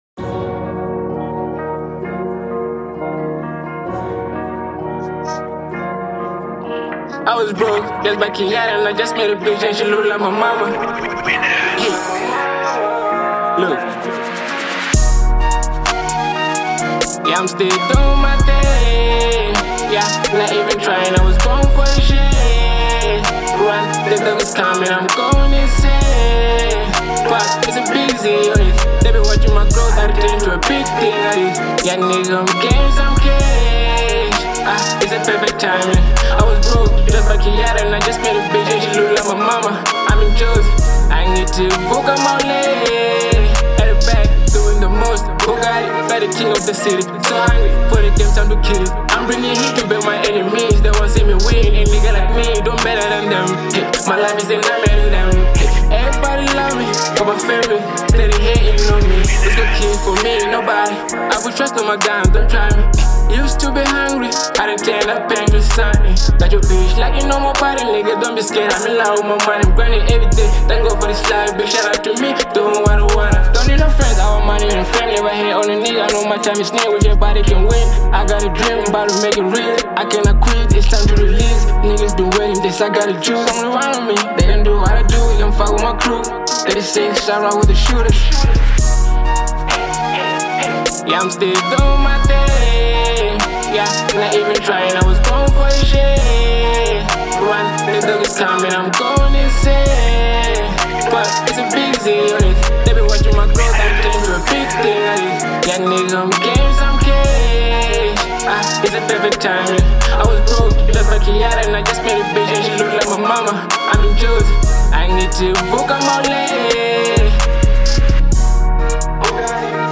02:14 Genre : Trap Size